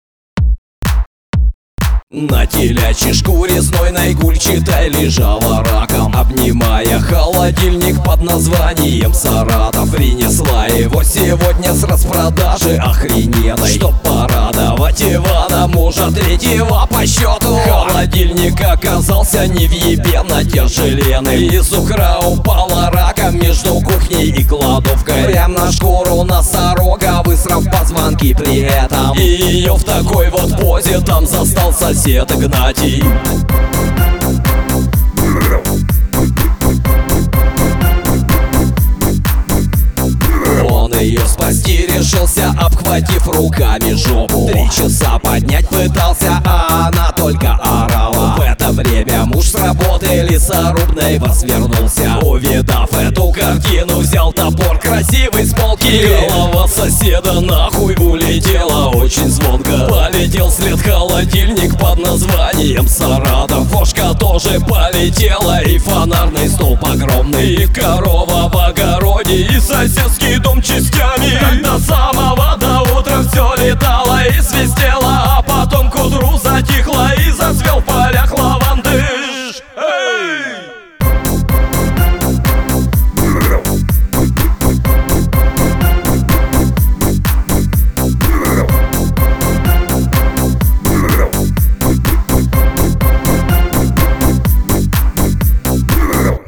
• Жанр: Альтернатива, Русская музыка